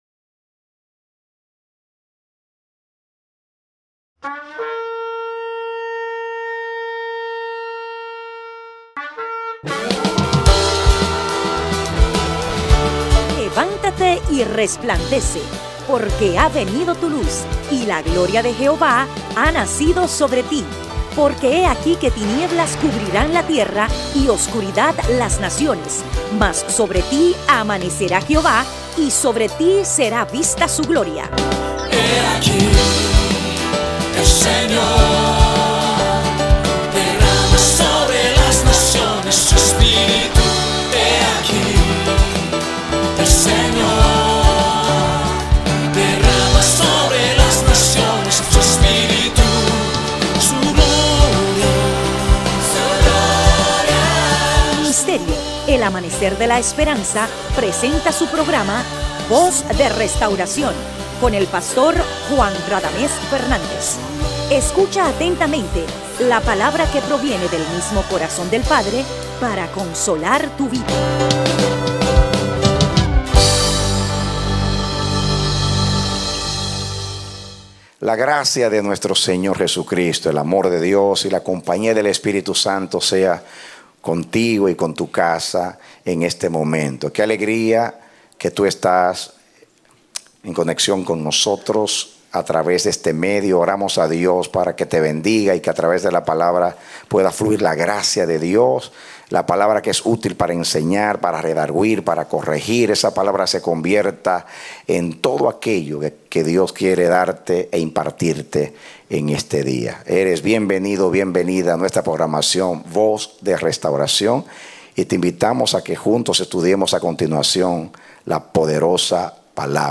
A mensaje from the serie "Mensajes." Predicado Octubre 16, 2014